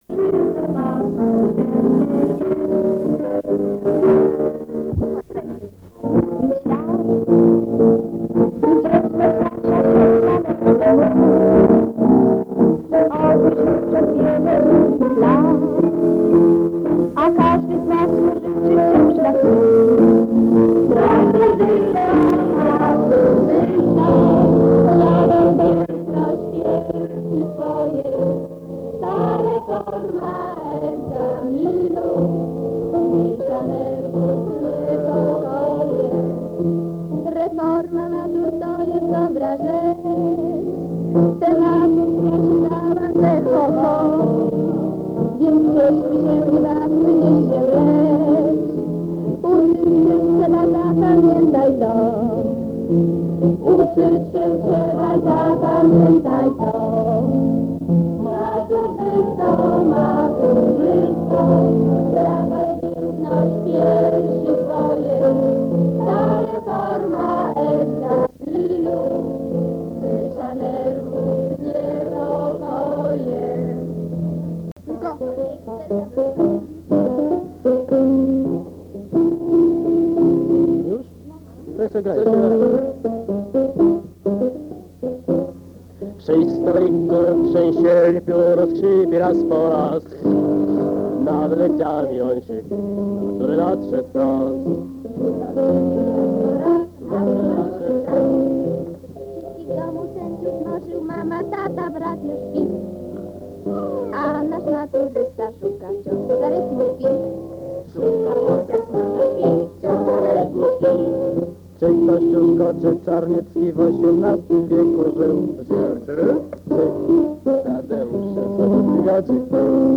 A zatem ten dźwięk, bardzo już zniszczony i niewyraźny, ma 42 lata.
Nagrywaliśmy to na archaicznym magnetofonie szpulowym "Tonette", a mikrofon, o niewątpliwie wspaniałej charakterystyce, był marki "Tonsil".
Jakiś czas temu przegrałem ją na kasetę - nie mając innych możliwości - akustycznie, przez mikrofon z głośnika.
A więc TUTAJ te strzępy dźwięków.